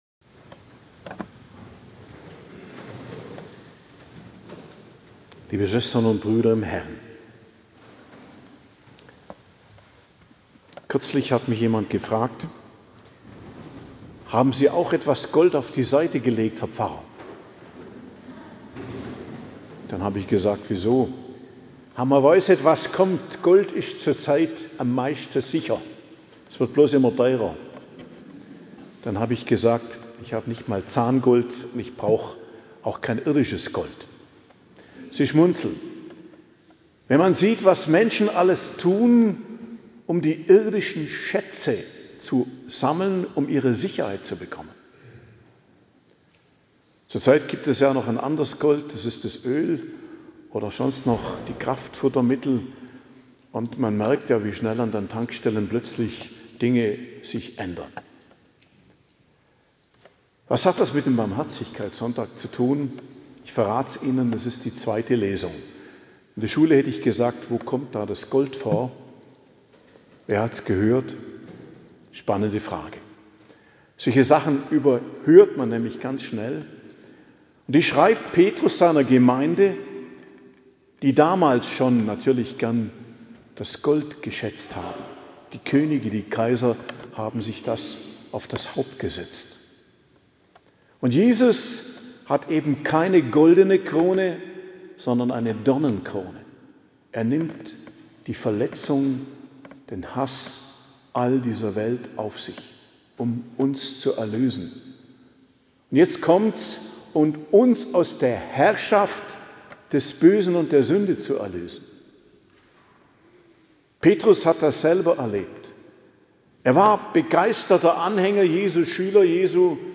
Predigt II zum Barmherzigkeitssonntag, 12.04.2026 ~ Geistliches Zentrum Kloster Heiligkreuztal Podcast